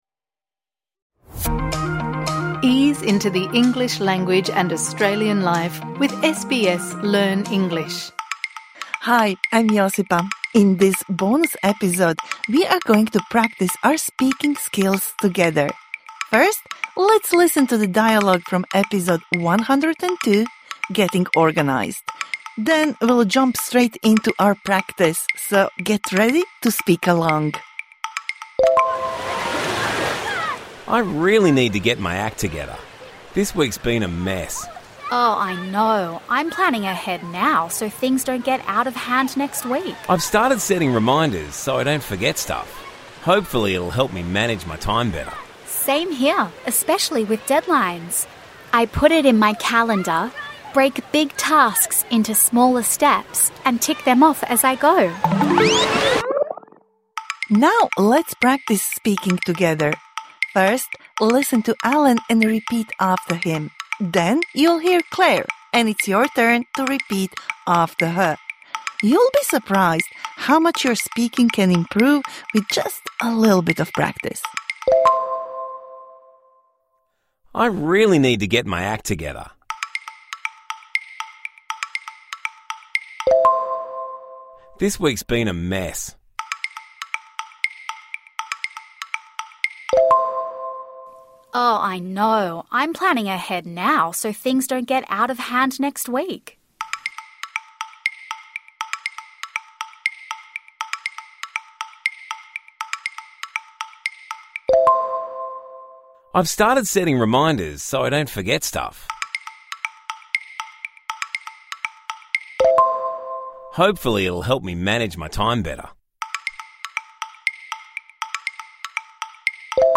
Practise speaking the dialogue from episode #102 Getting organised (Med)
This bonus episode provides interactive speaking practice for the words and phrases you learnt in #102 Getting organised (Med) Don't be shy - just try!